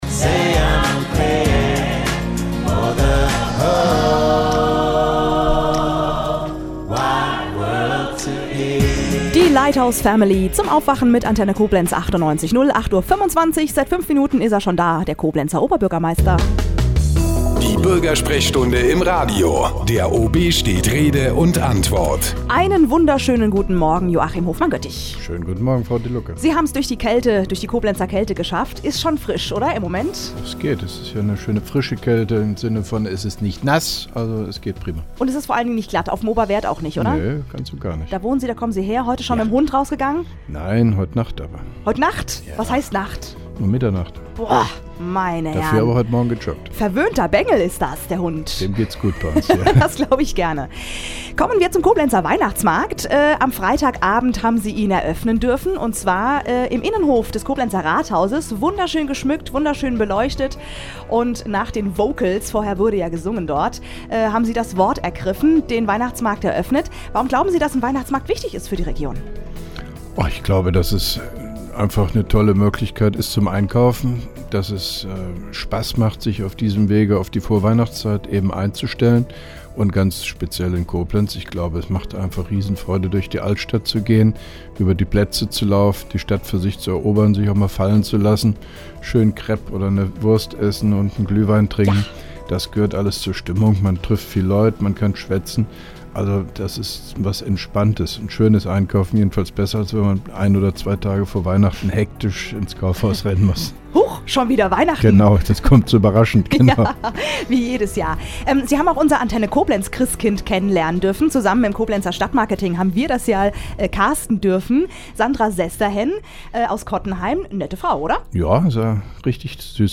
(1) Koblenzer OB Radio-Bürgersprechstunde 23.11.2010
Interviews/Gespräche